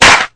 Clap (4).wav